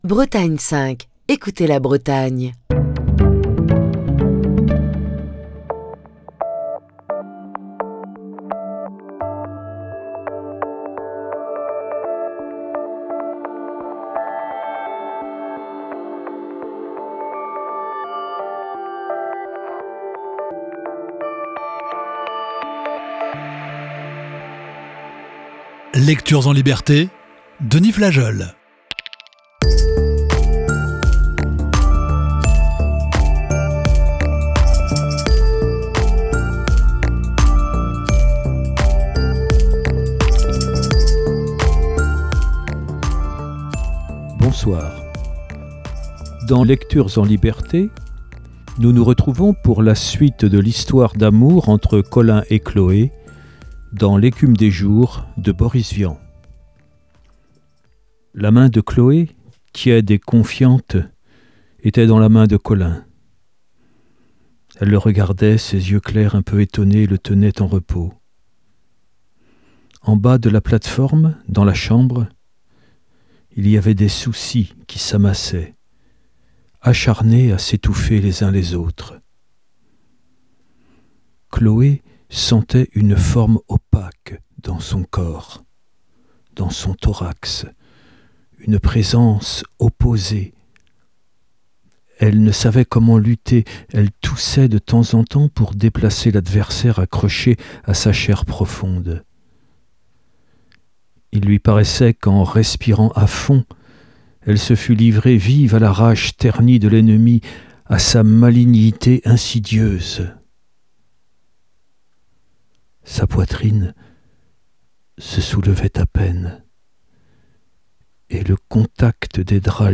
avec la lecture de "L'écume des jours".